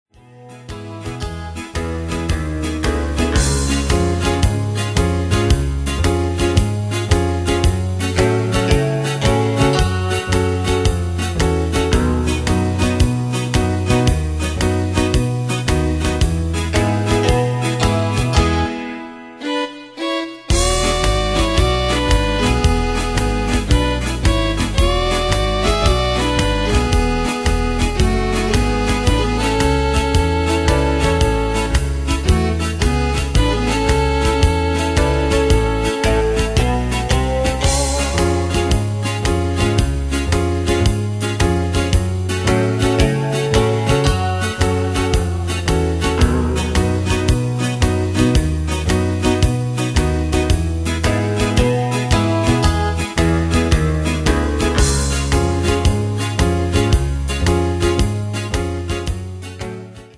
Style: Country Année/Year: 1993 Tempo: 112 Durée/Time: 4.13
Danse/Dance: TwoSteps Cat Id.
Pro Backing Tracks